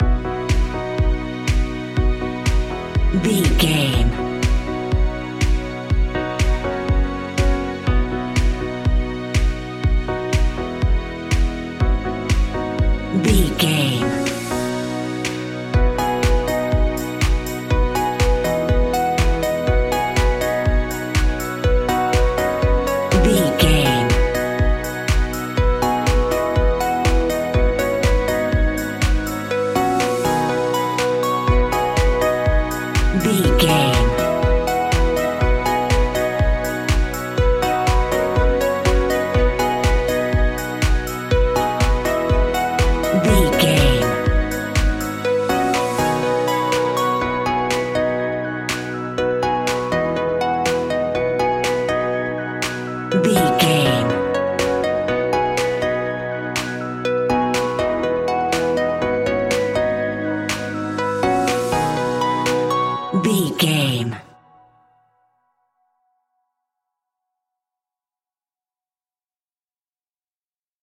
Modern Anthemic Corporate Music 60 Sec.
Ionian/Major
groovy
uplifting
energetic
bouncy
synthesiser
drums
strings
electric piano
electronic